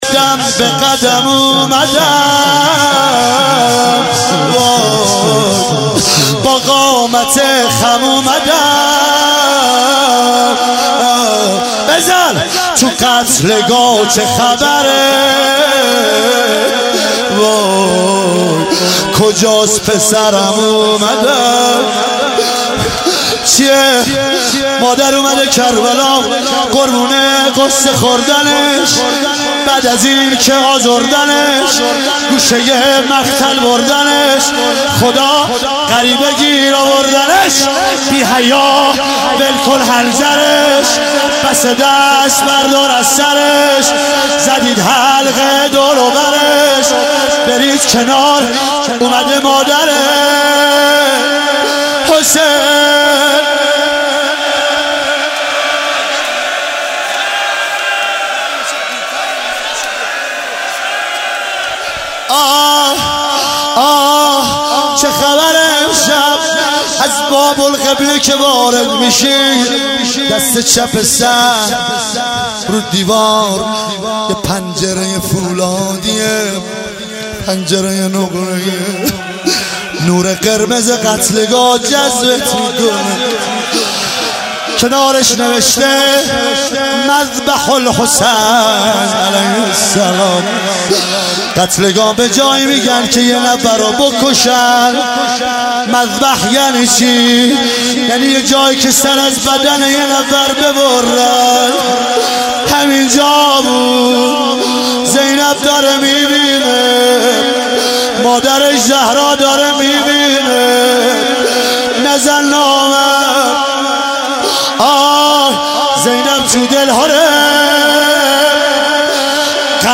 شب هشتم محرم 96 - شور - قدم به قدم اومدم با قامت خم اومدم
محرم 96